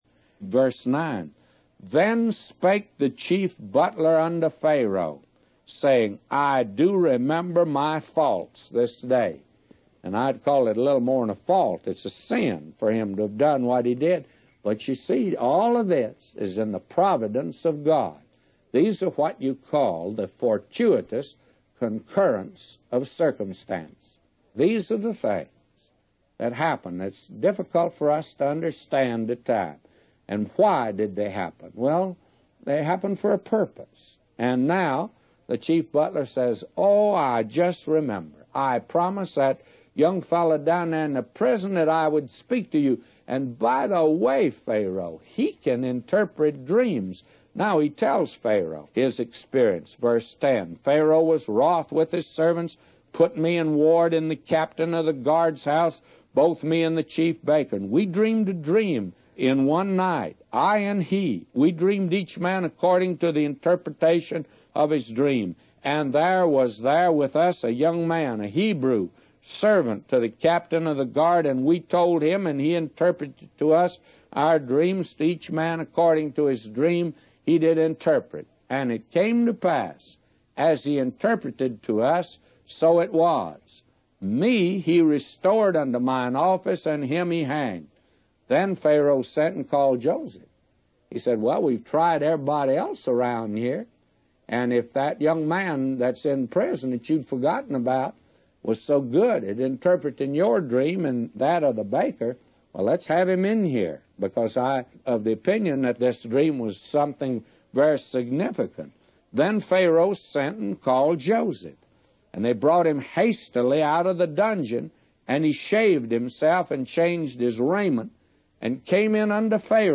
A Commentary By J Vernon MCgee For Genesis 41:9-999